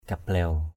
/ka-blɛʊ/ (t.) lệch = penché d’un côté. maaom talmo kablaiw m_a> tL_m% k=b*| đội mũ lệch = porter le chapeau sur le côté.